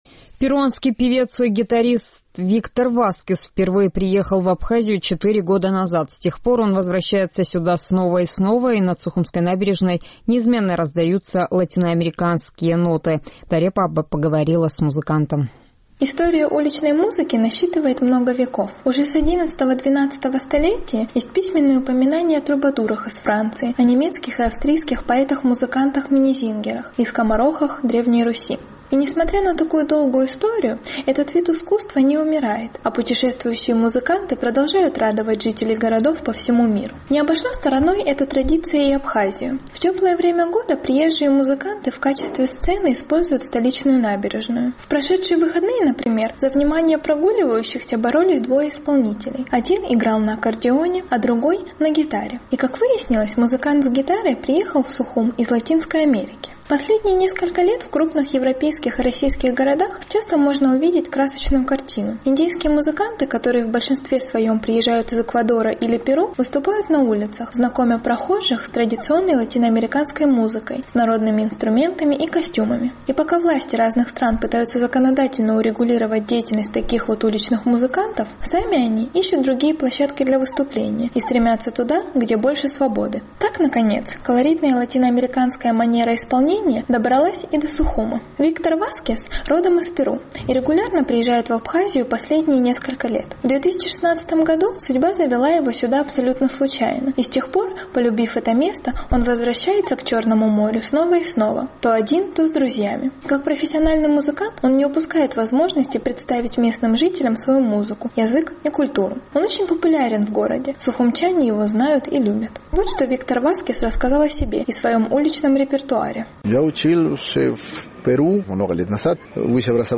Латиноамериканские мелодии над сухумской набережной